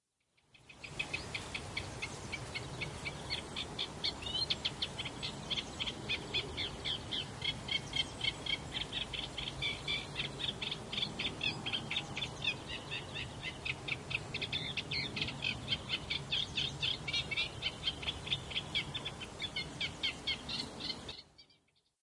苇莺r
描述：一只芦苇莺（Acrocephalus scirpaceus）在被风吹动的芦苇丛中唱歌。现在用大胆的方式进行清洗。